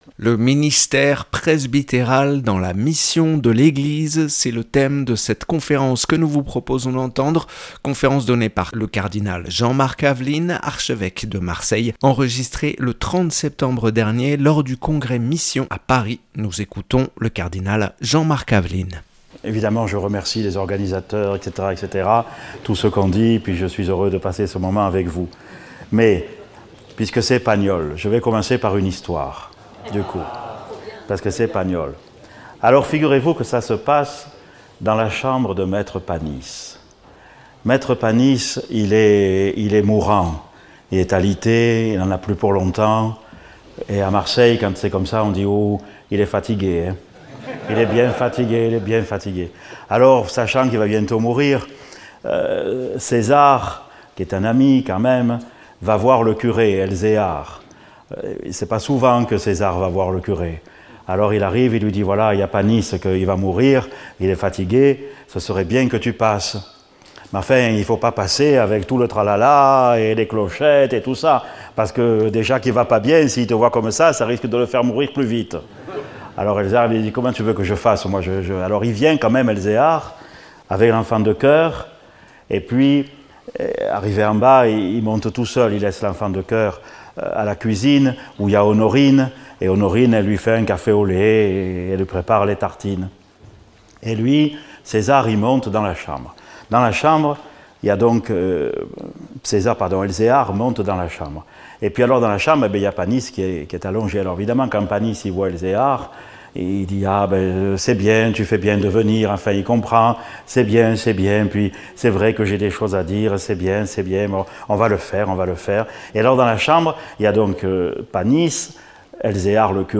Conférence du Cardinal Jean-Marc Aveline, archevêque de Marseille. (Enregistré le 30/09/2022 lors du Congrès Mission à Paris).